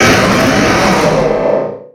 Cri de Méga-Galeking dans Pokémon X et Y.
Cri_0306_Méga_XY.ogg